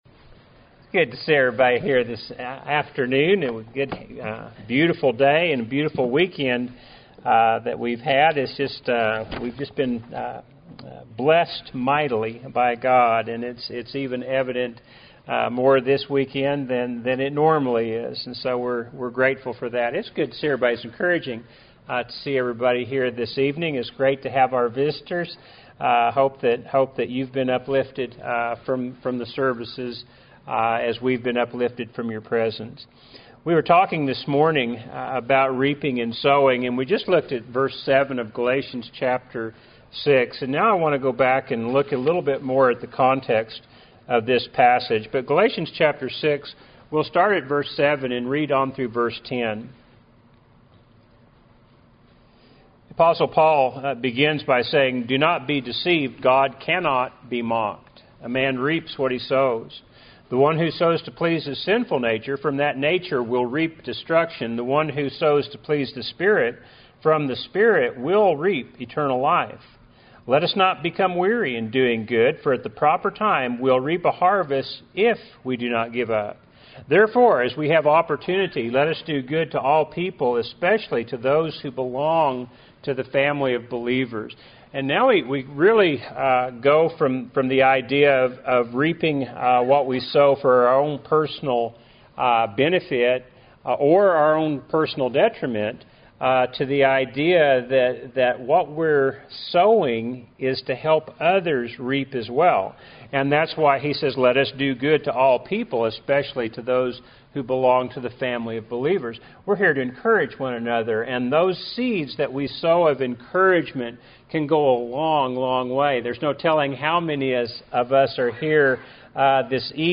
Happy Church of Christ Listen to Sermons
Weekly sermons